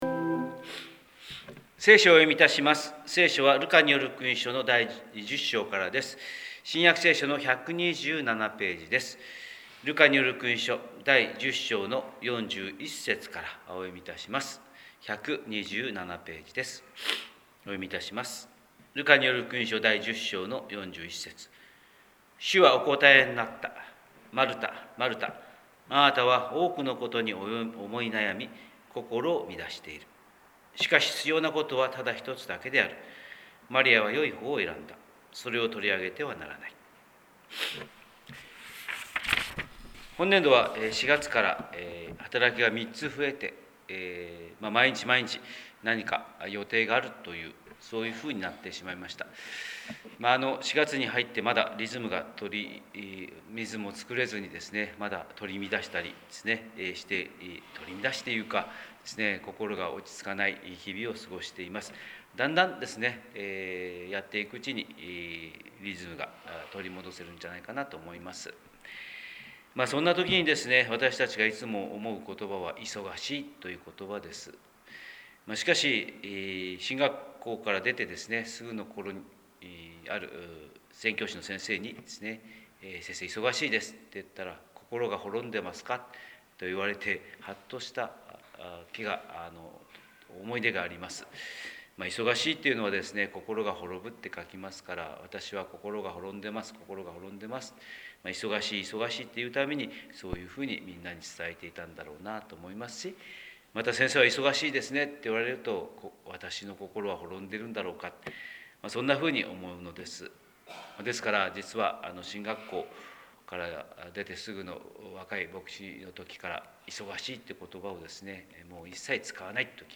神様の色鉛筆（音声説教）: 広島教会朝礼拝250423